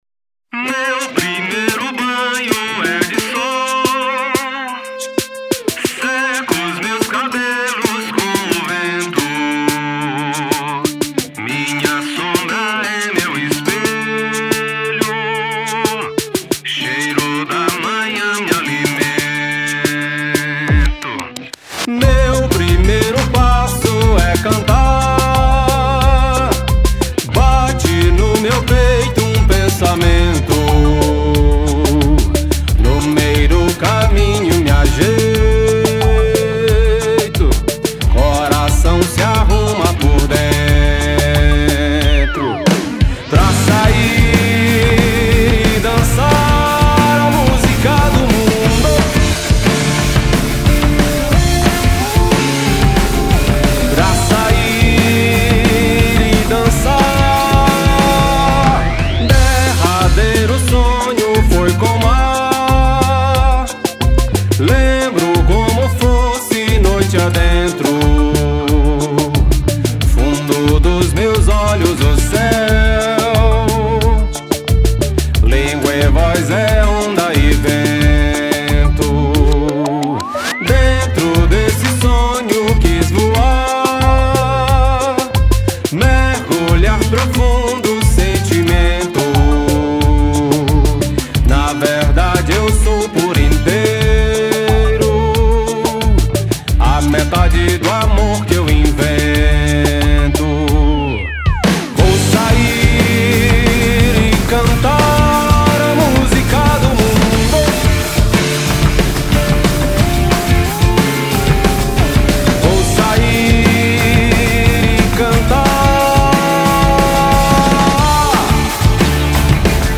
1272   03:44:00   Faixa:     Rock Nacional